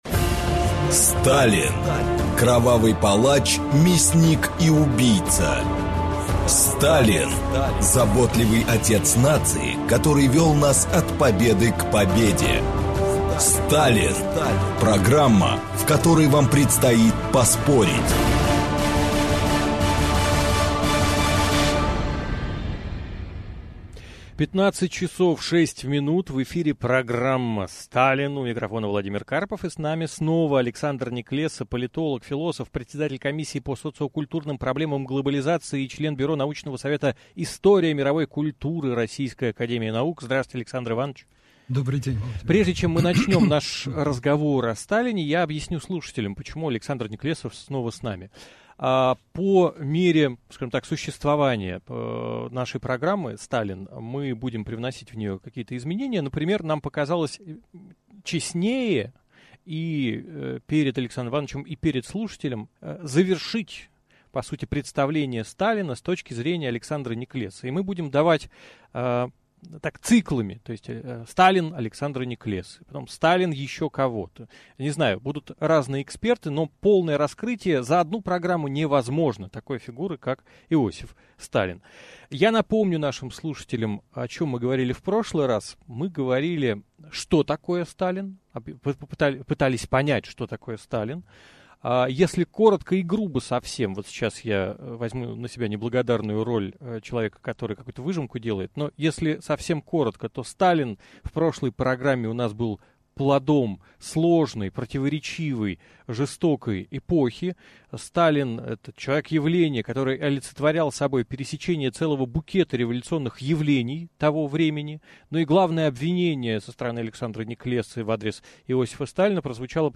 Аудиокнига Феномен Сталина в русском сознании. Часть 2 | Библиотека аудиокниг